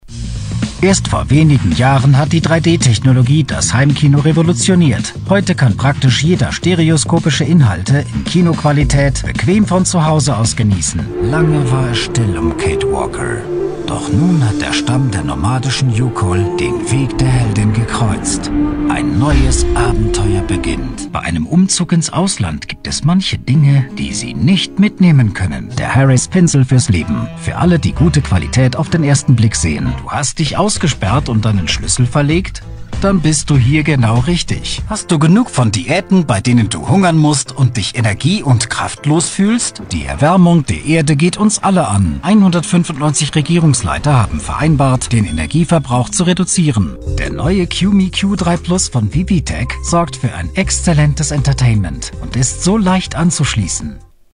德语男声 宣传片讲述浑厚大气沉稳 大气浑厚磁性|沉稳